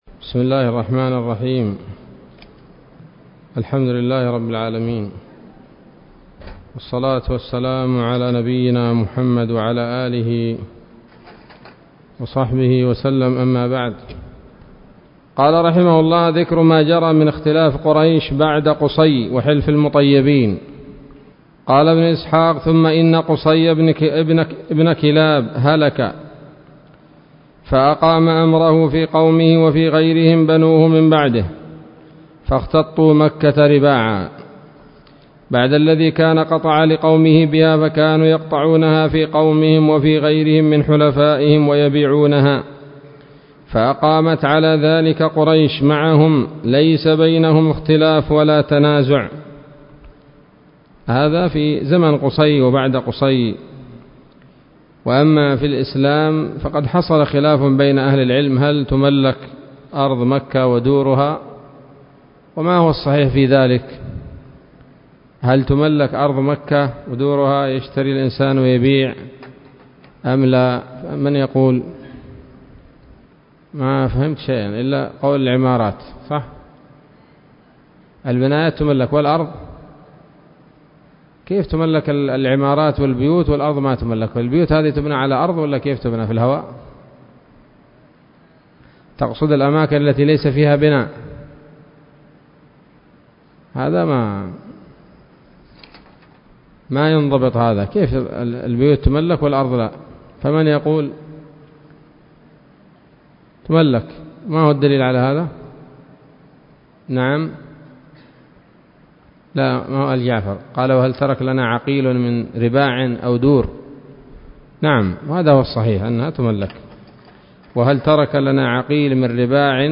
الدرس الحادي عشر من التعليق على كتاب السيرة النبوية لابن هشام